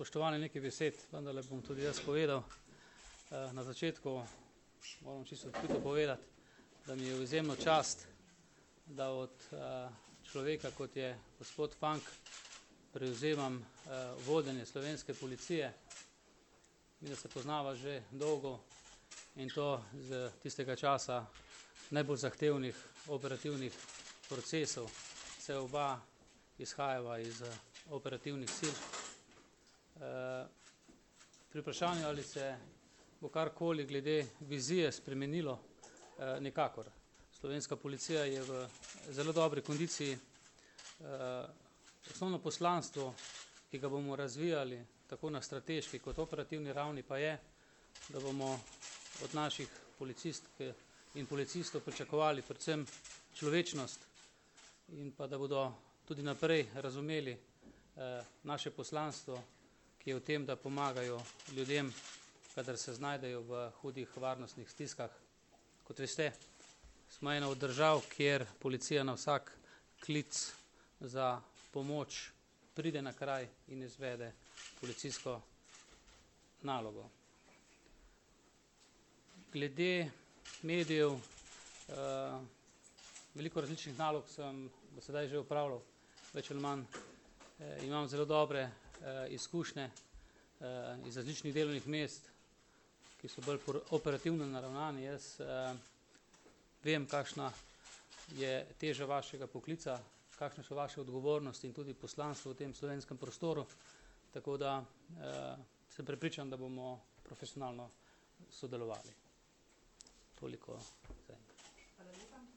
Zvočni posnetek izjave Simona Veličkega (mp3)